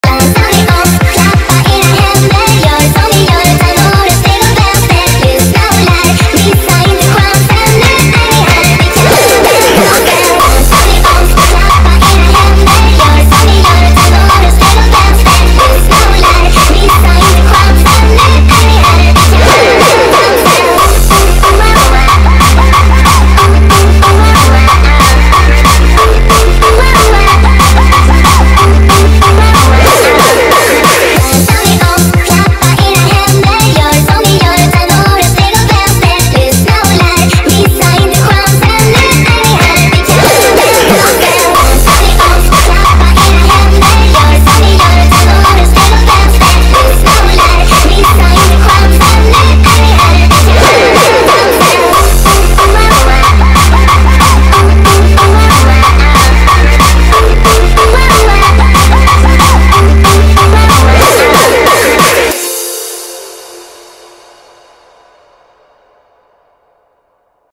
فانک
ماشینی
باشگاهی